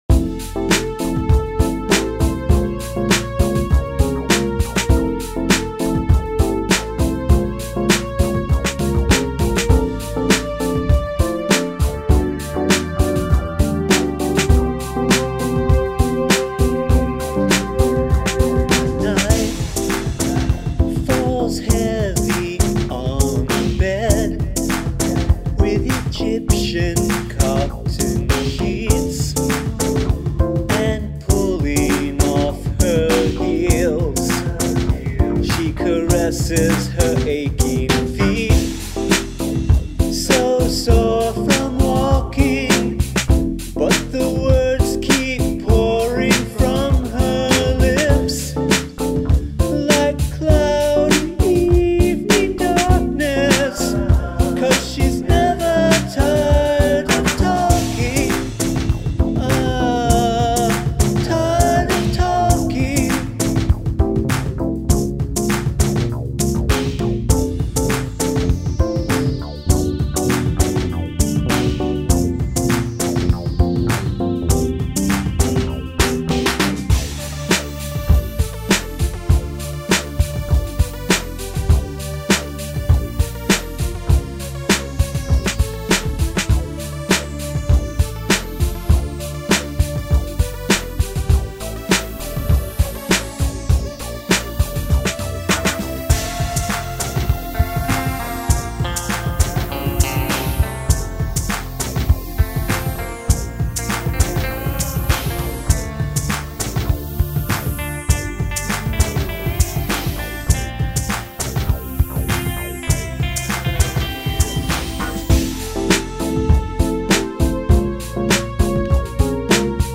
Lyric consists of only one sentence.
Much nicer drum tone than last time!
I like the lead guitar part.